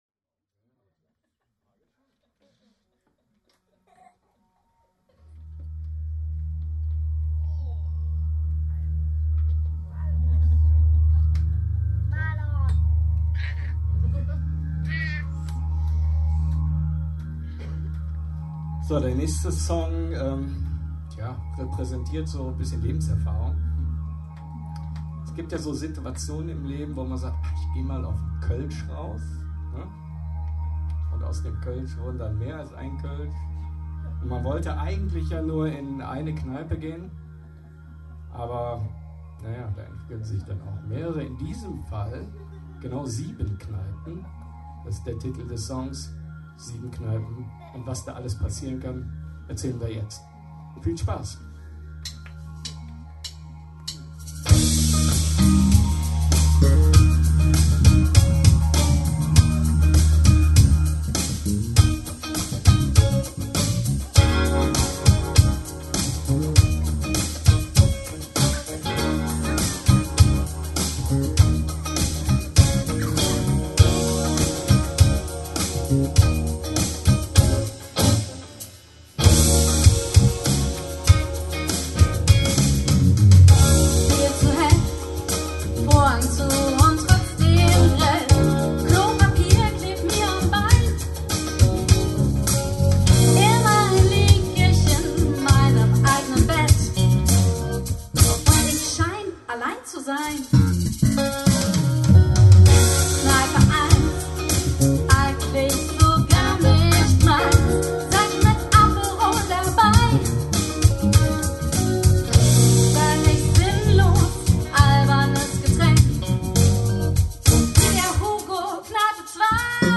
Keyboards
Drums
Percussion
Bass
Guitar.